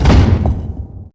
DOORS1C.WAV